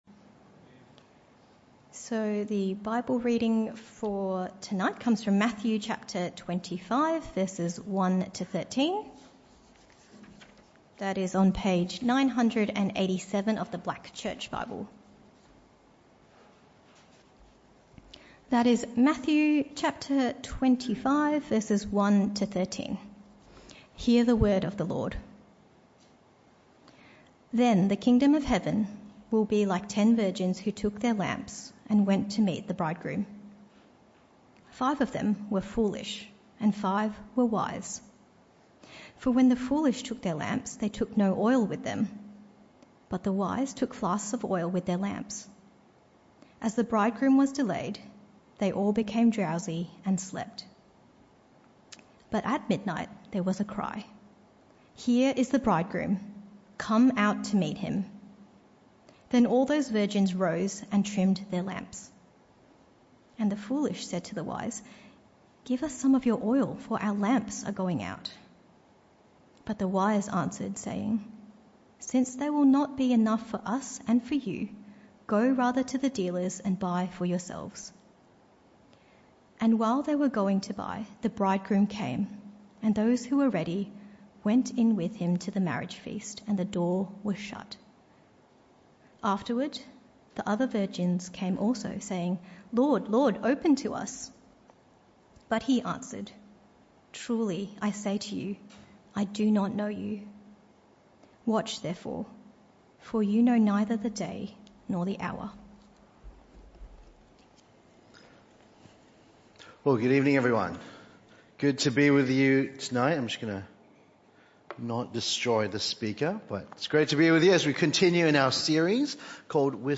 This talk was part of the PM Service series entitled Whispers Of The Kingdom.